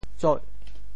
截（巀） 部首拼音 部首 戈 总笔划 14 部外笔划 10 普通话 jié 潮州发音 潮州 zoih8 白 中文解释 截 <动> (形声。